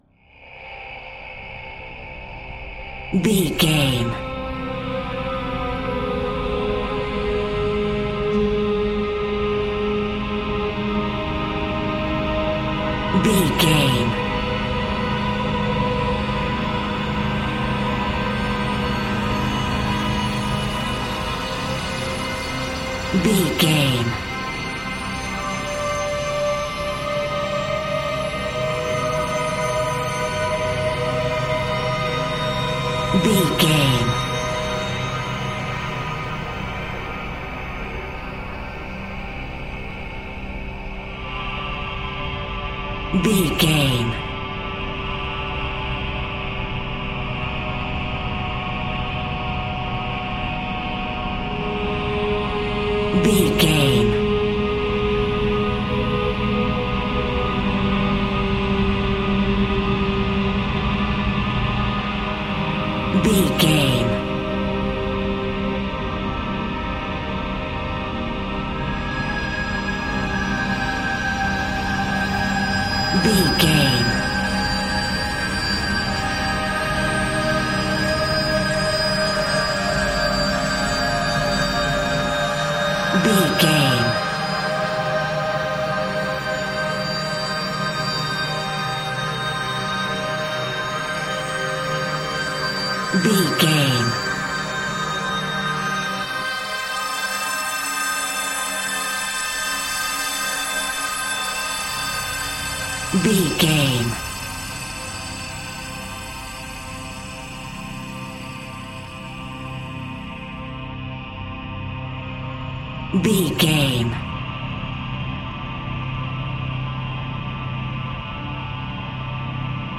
Thriller
Atonal
tension
ominous
dark
suspense
dramatic
eerie
synthesizer
cymbals
gongs
viola
french horn trumpet
taiko drums
timpani